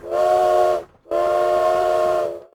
sounds_train_whistle.ogg